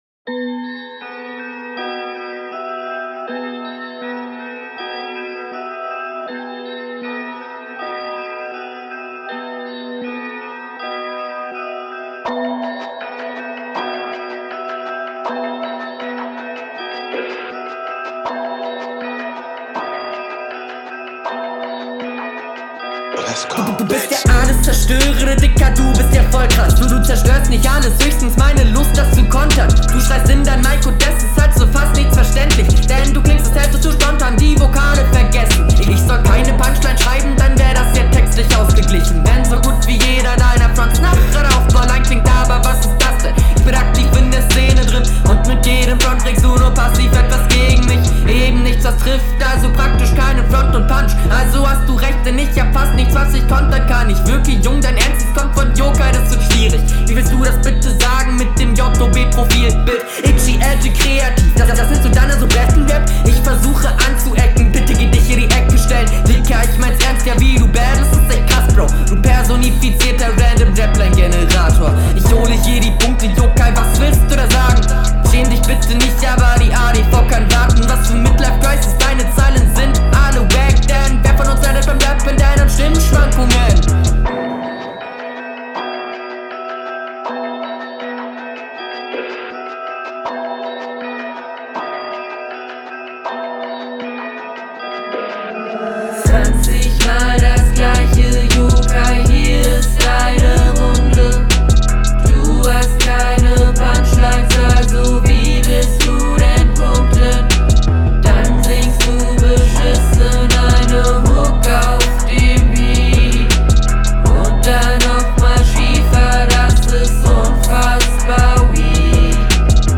Der Flow ist hier ziemlich gut.